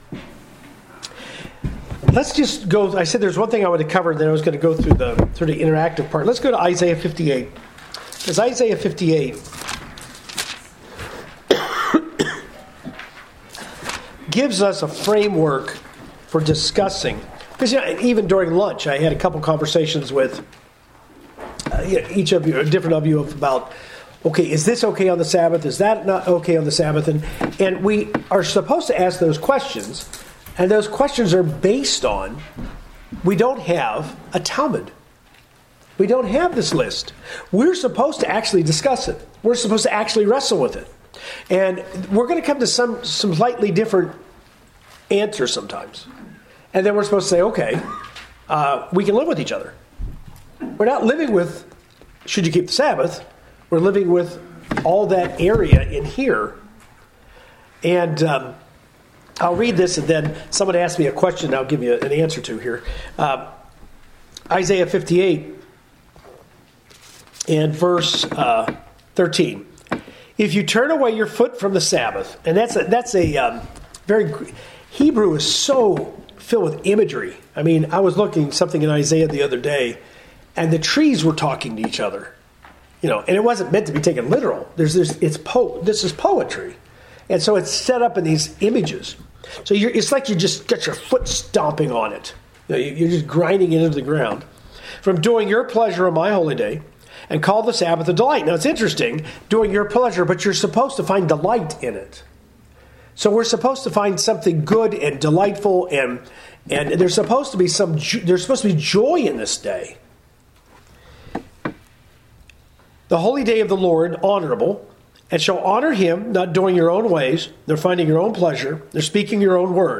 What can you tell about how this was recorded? This Bible Study uses an interactive format to discuss what scriptural challenges people have encountered regarding their observance of the 7th-day Sabbath.